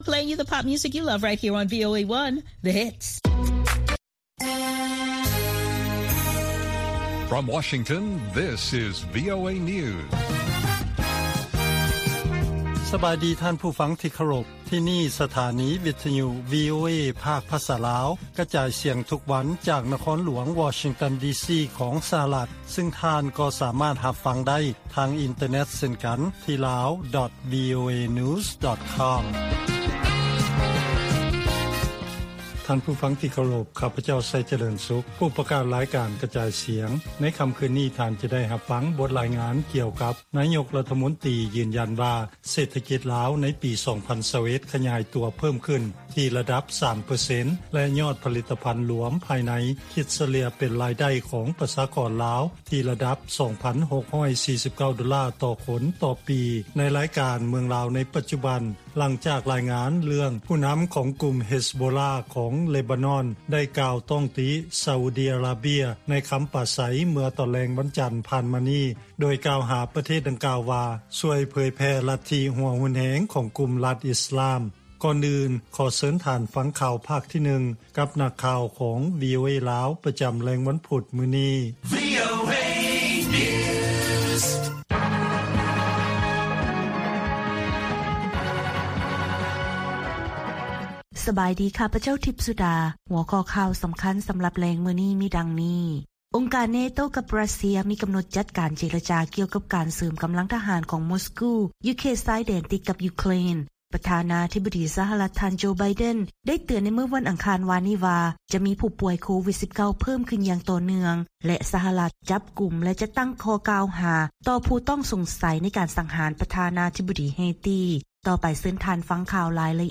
ລາຍການກະຈາຍສຽງຂອງວີໂອເອ ລາວ: ອົງການ NATO ກັບ ຣັດເຊຍ ມີກຳນົດຈັດການເຈລະຈາ ກ່ຽວກັບ ການເສີມກຳລັງທະຫານຂອງ ມົສກູ ຢູ່ເຂດຊາຍແດນຕິດກັບ ຢູເຄຣນ